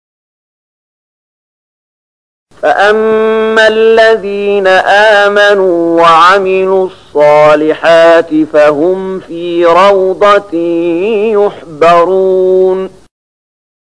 030015 Surat Ar-Ruum ayat 15 dengan bacaan murattal ayat oleh Syaikh Mahmud Khalilil Hushariy: